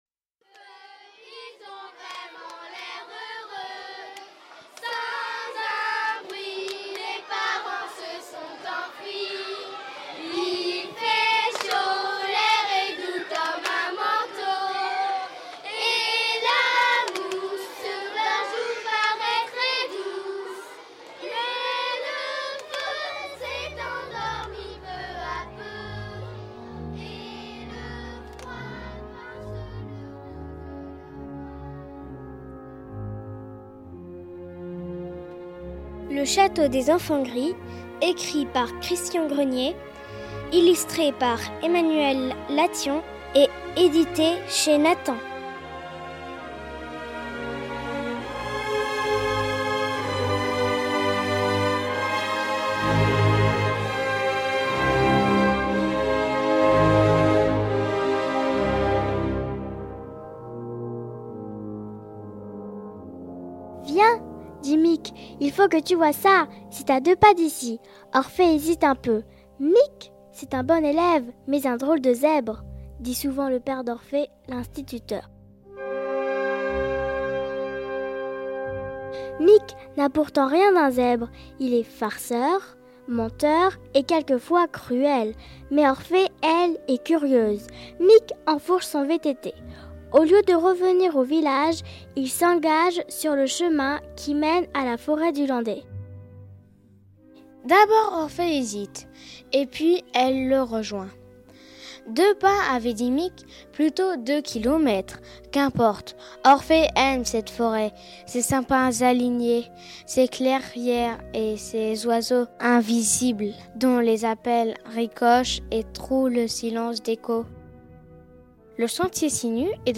Lecture — Le son sur la langue